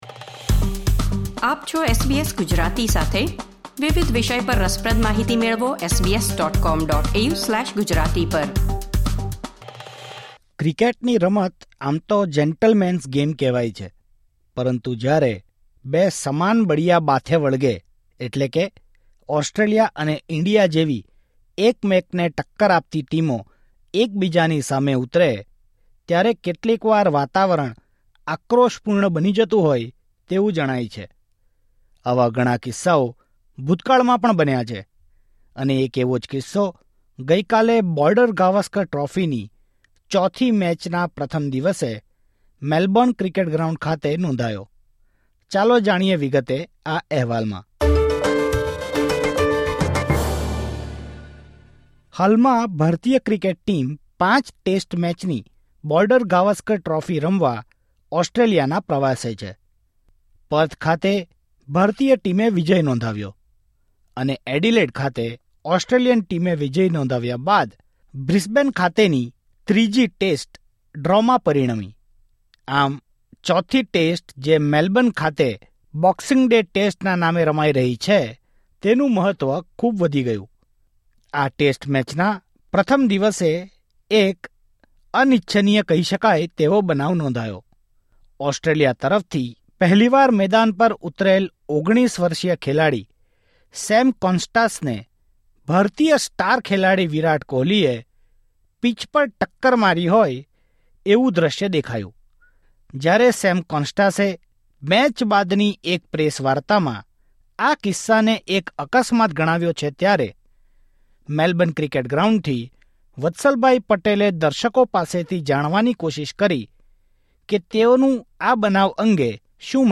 બોક્સિંગ ડે ટેસ્ટના પ્રથમ દિવસે બનેલ અનિચ્છનીય બનાવ પર દર્શકોએ આપી પ્રતિક્રિયા